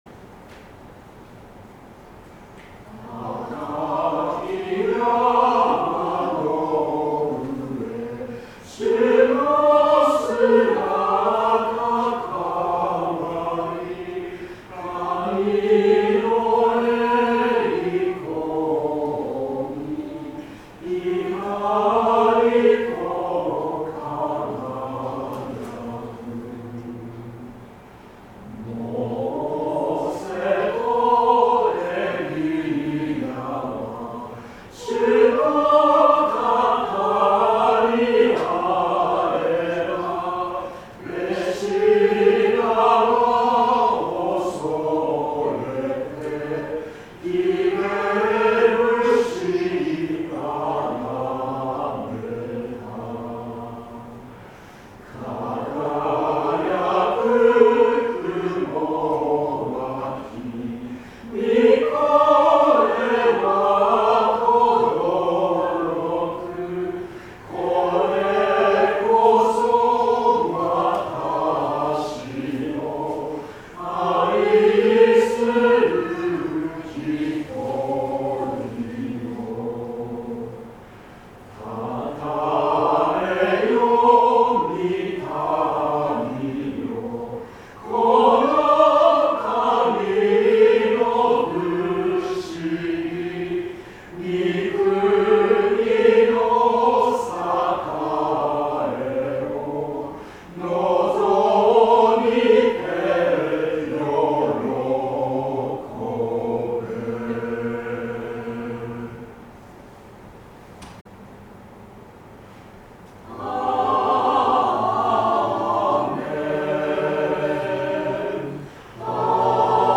聖歌隊奉唱・献花